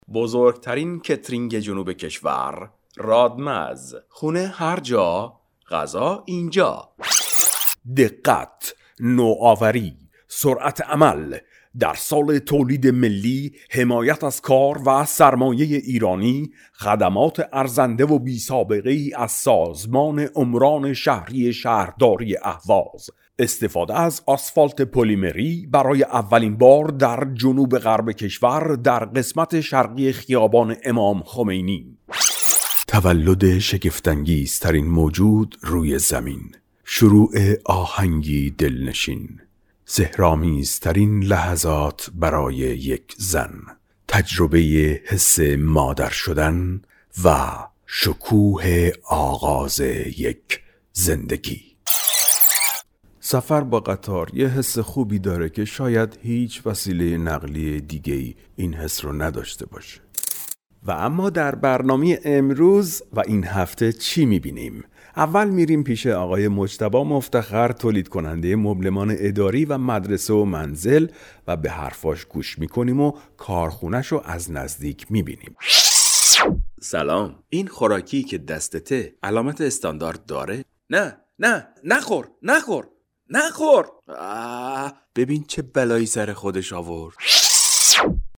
Male
Adult
English (Local accent)